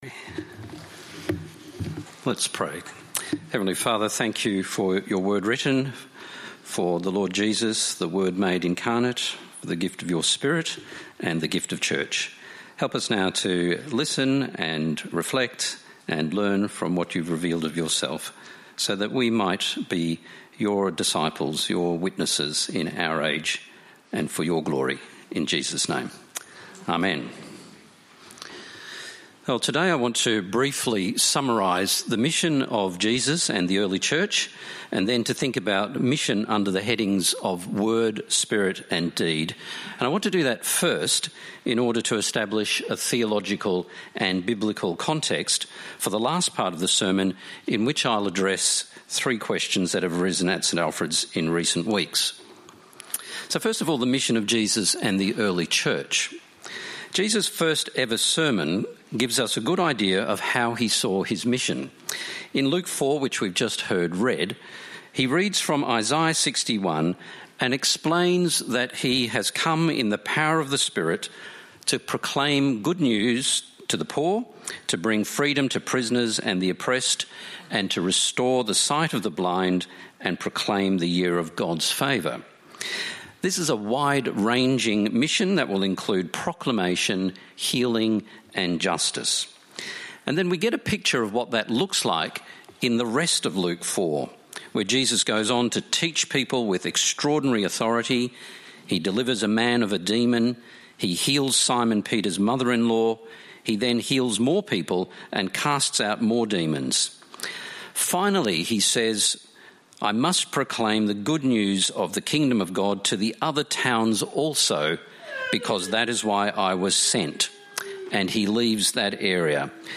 St Alfred's is an Anglican Church in Blackburn North, Victoria, Australia
This sermon is not part of a Sermon Series and stands alone.